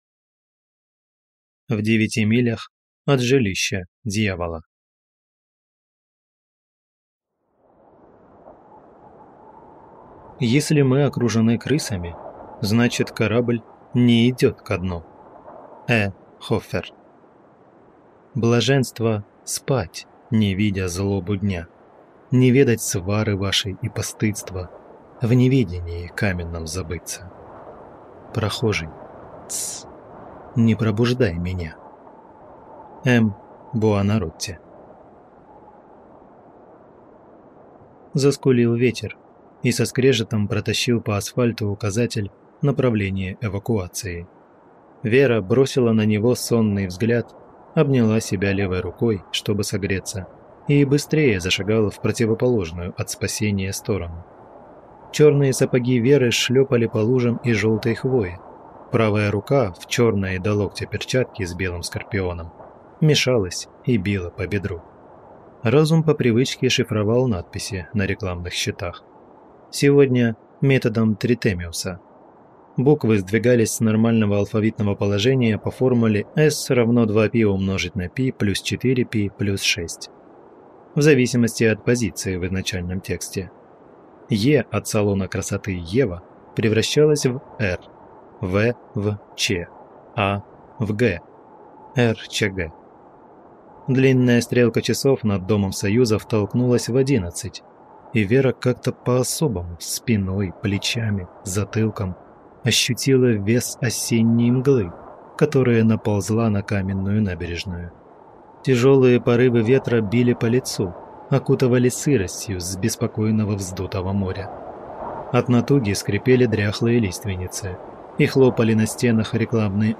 Аудиокнига В девяти милях от жилища дьявола | Библиотека аудиокниг